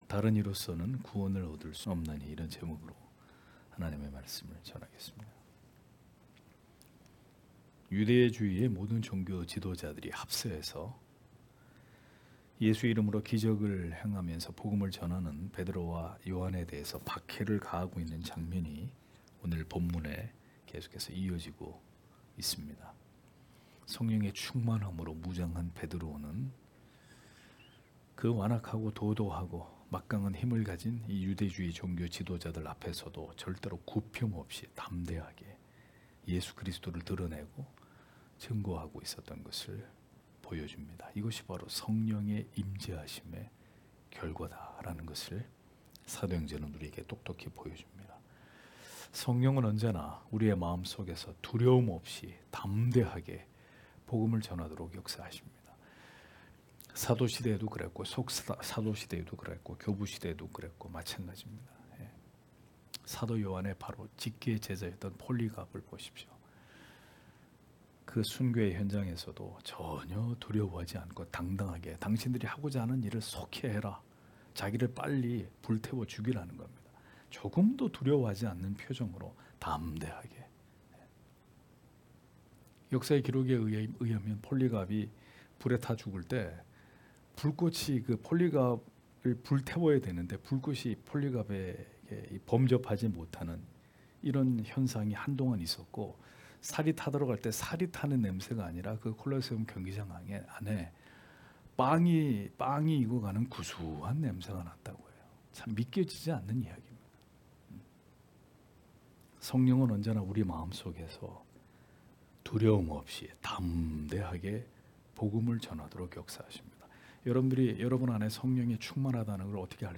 금요기도회 - [사도행전 강해 26] 다른 이름으로 구원을 얻을 수 없나니 (행 4장 11-18절)